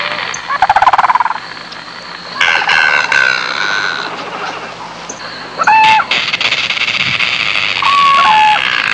mangust-mungos-sp..mp3